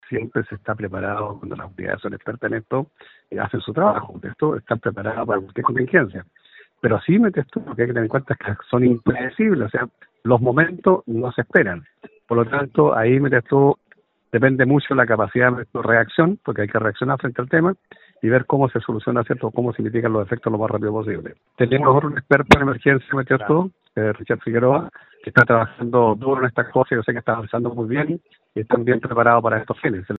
En el caso de Osorno, el alcalde Jaime Bertín indica que si las unidades son expertas en su trabajo, siempre se está preparado. Si bien son situaciones imprevisibles, depende de la capacidad de reacción de las unidades de emergencia.